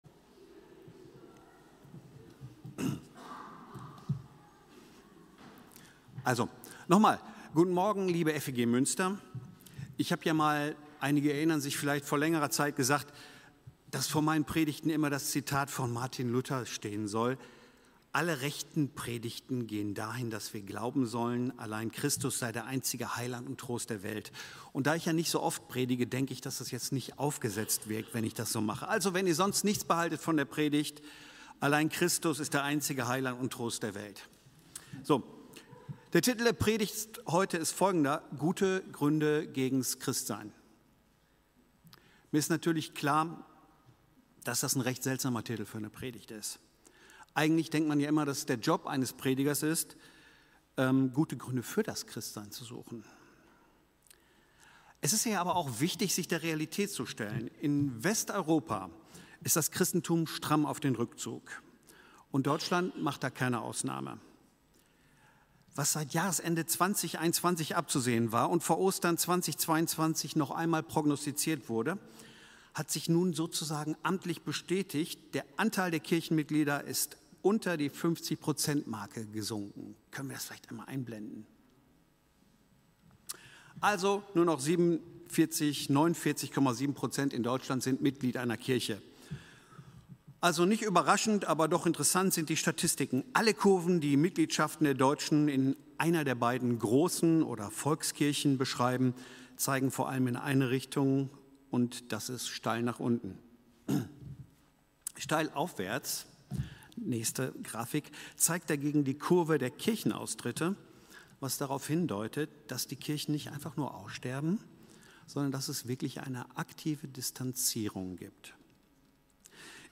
Predigt-am-10.03-online-audio-converter.com_.mp3